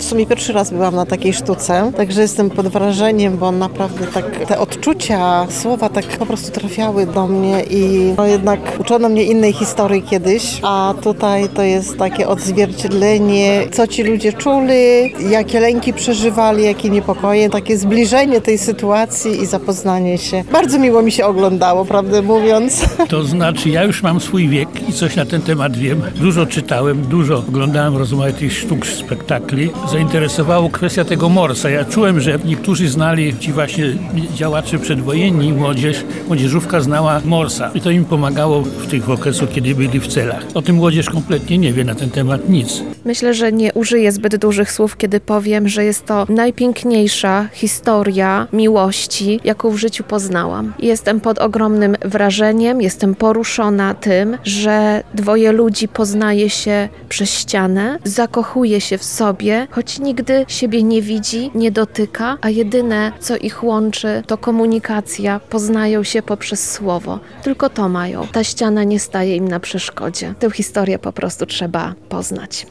sonda
widownia.mp3